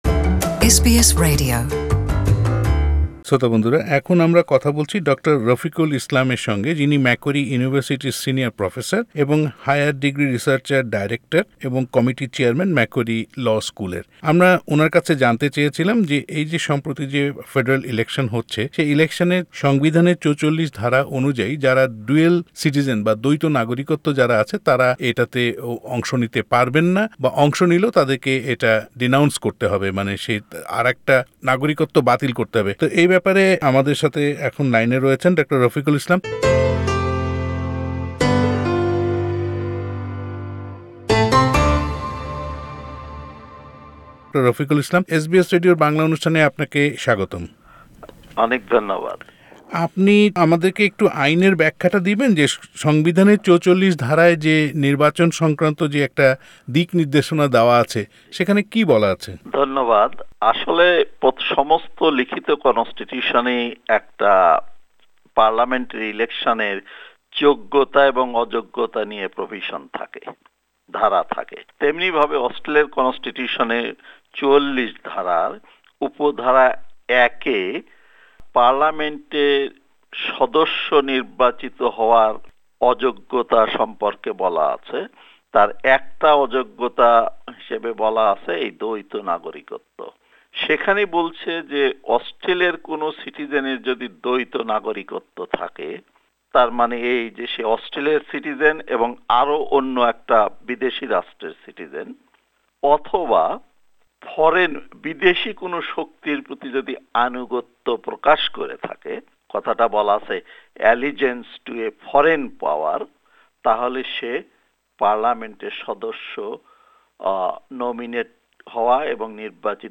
সাক্ষাৎকারটি